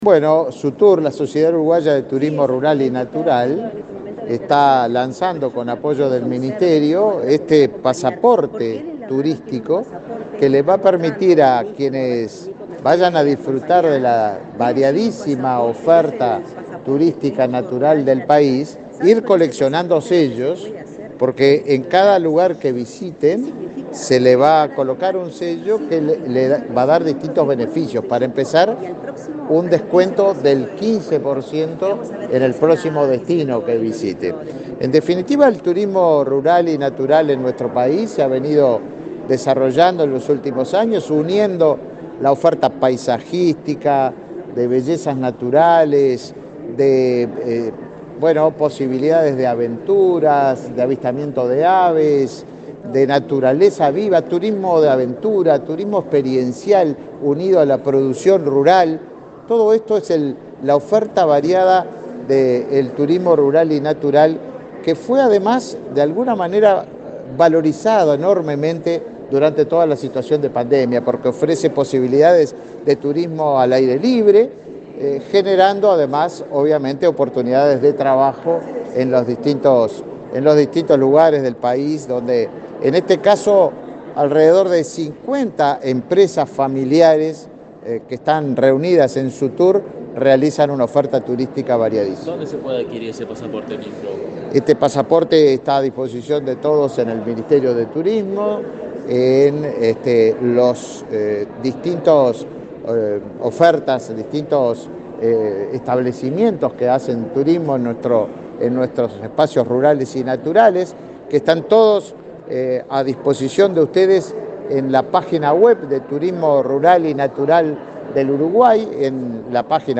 Declaraciones a la prensa del ministro de Turismo, Tabaré Viera
Declaraciones a la prensa del ministro de Turismo, Tabaré Viera 28/06/2022 Compartir Facebook X Copiar enlace WhatsApp LinkedIn Este martes 28 en la Torre Ejecutiva, el ministro Tabaré Viera participó en el lanzamiento del Pasaporte Turismo Rural y Natural y, luego, dialogó con la prensa.